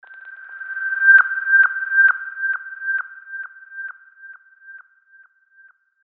SFX – SONAR LIKE SOUND
SFX-SONAR-LIKE-SOUND.mp3